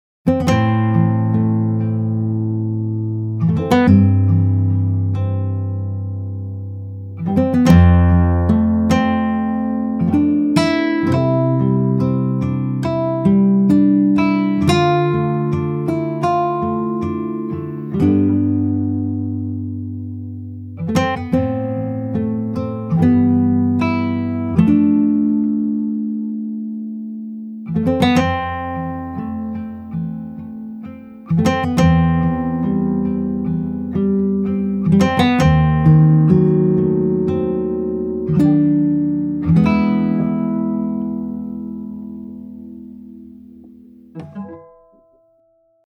stereo acoustic nylon guitar